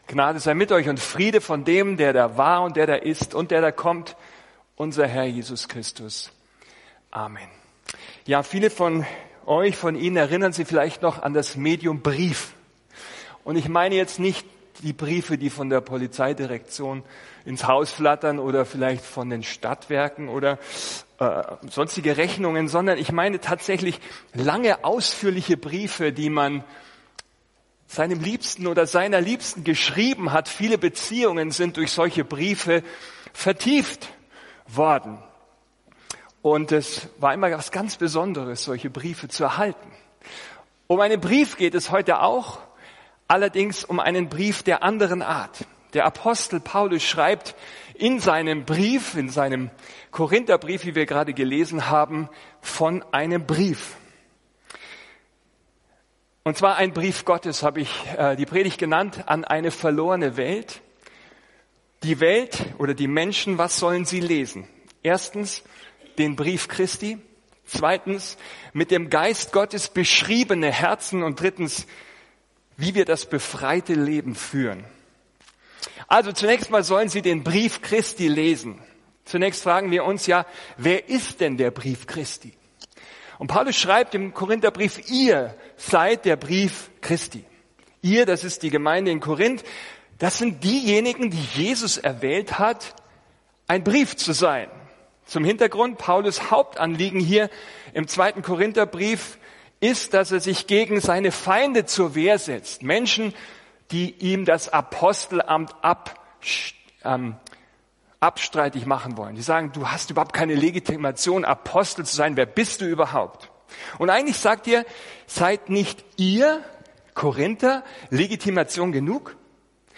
Ein Studienblatt zur Predigt ist im Ordner “Notizen” verfügbar